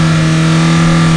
1 channel
MOTOR4.mp3